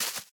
Minecraft Version Minecraft Version latest Latest Release | Latest Snapshot latest / assets / minecraft / sounds / block / azalea_leaves / break5.ogg Compare With Compare With Latest Release | Latest Snapshot